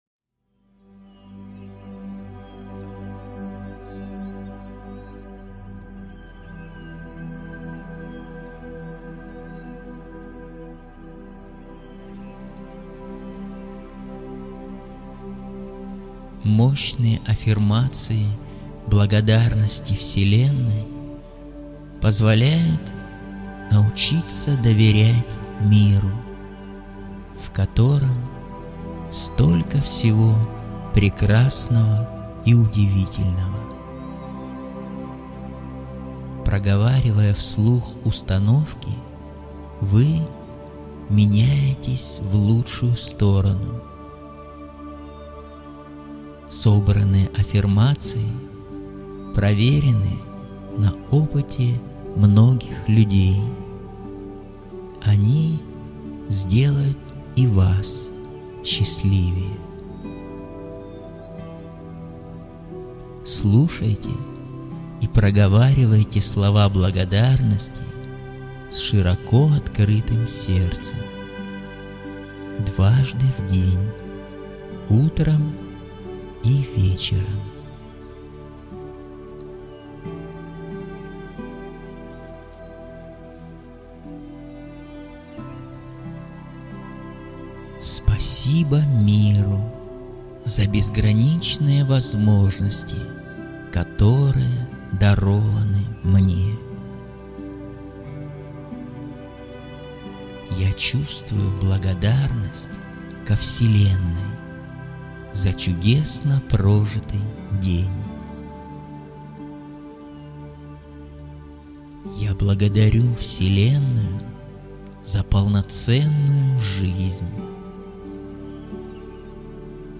Звуки аффирмаций благодарности - скачать и слушать онлайн бесплатно mp3